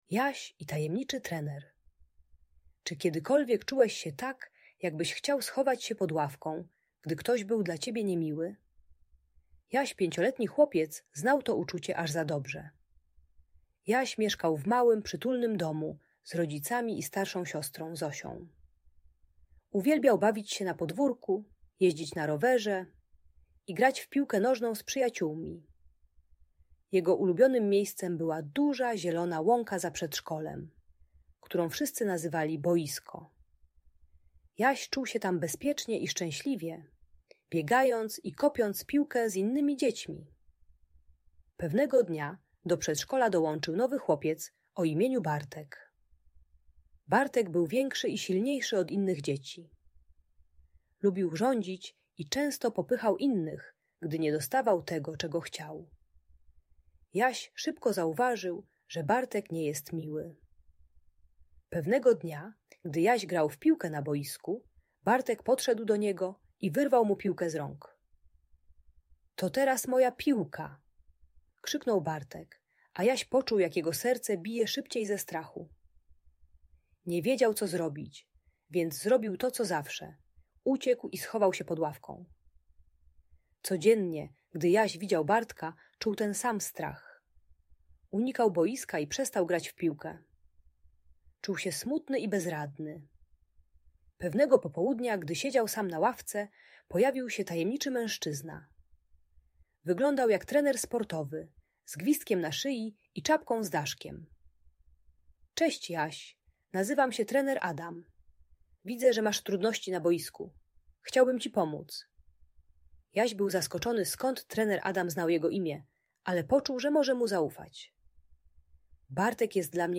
Historia o Jasiu i Tajemniczym Trenerze - Audiobajka